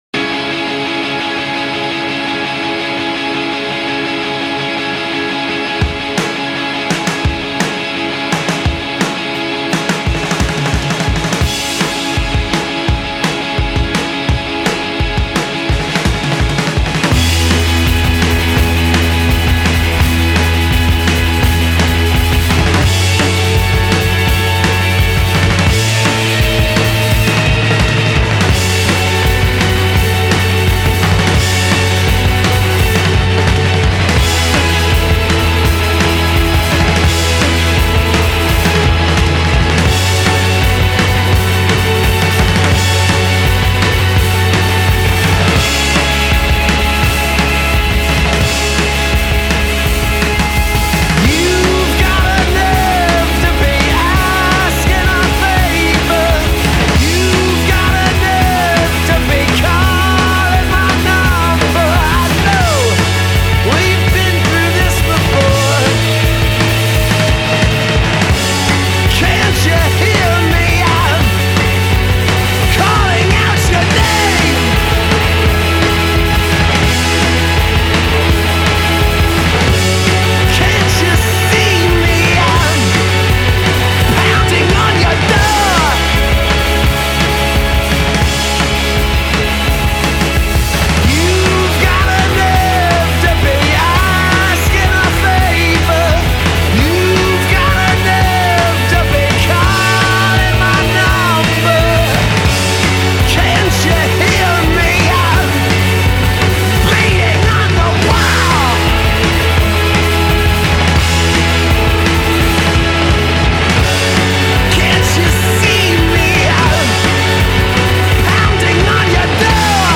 There’s so much energy in it.